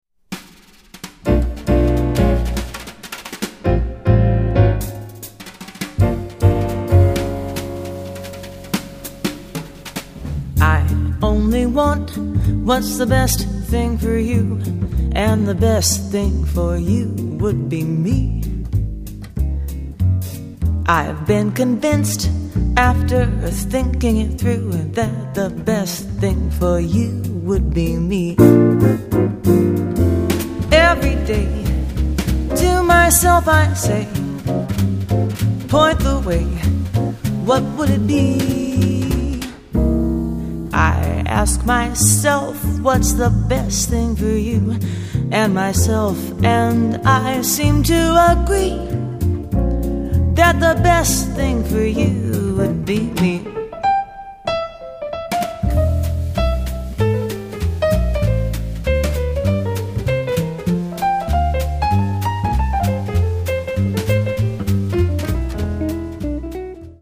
jazz singers